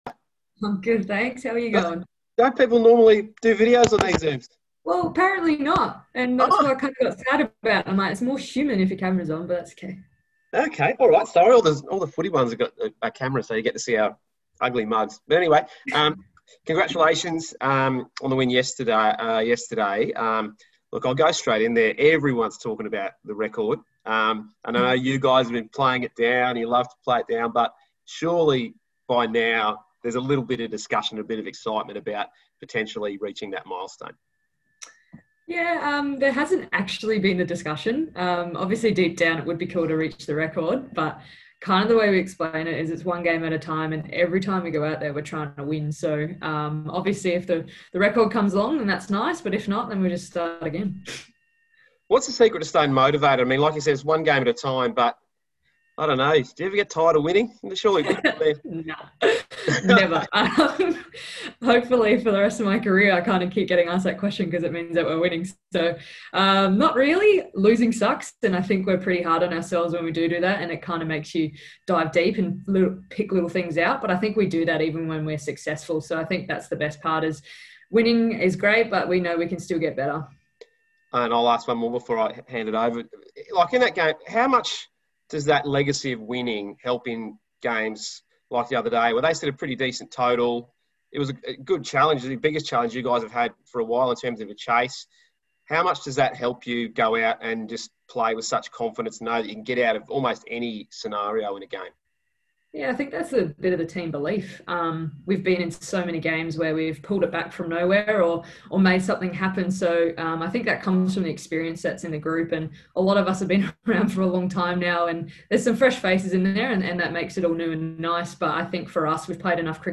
Pace bowler Megan Schutt spoke to media via video conference today, discussing Australia's Bose Bowl success and streak of 20 consecutive One-Day International victories.